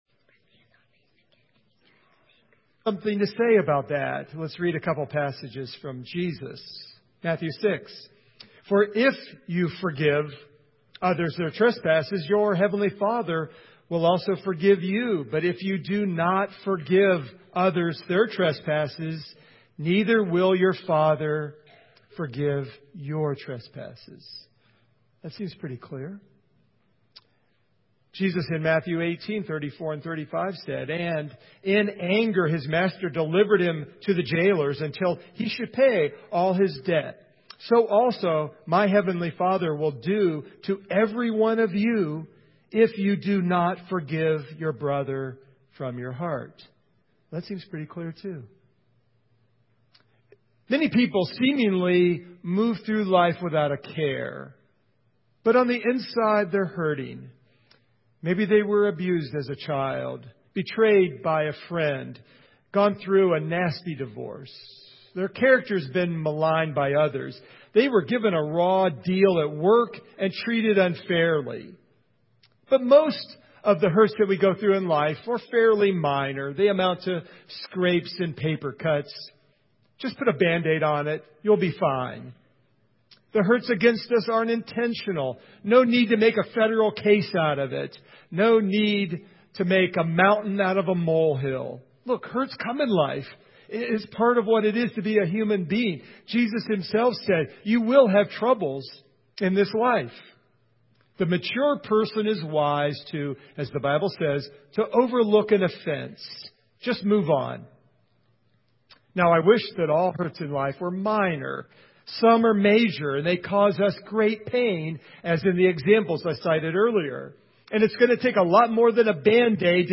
Matthew 6:14-15 Service Type: Sunday Morning I share three ways that we often deal with major hurts and the third forgiveness is the only way to be free inside.